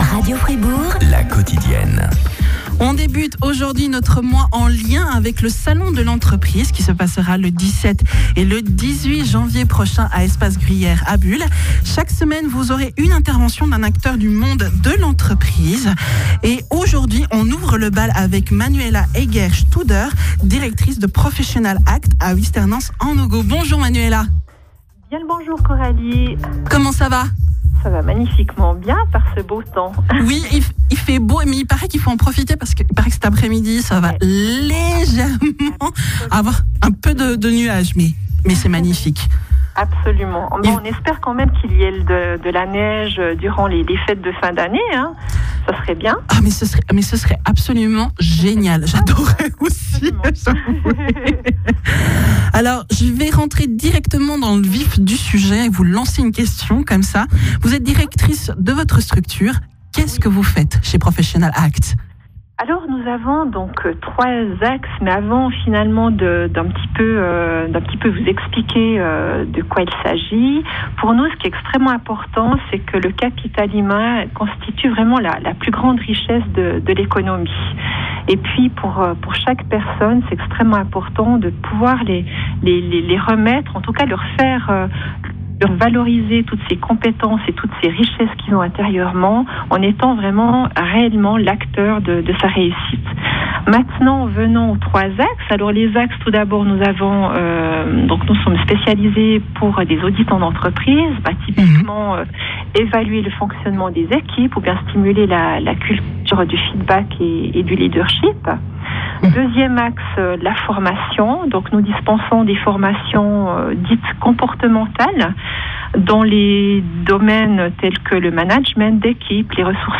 Interview sur Radio Fribourg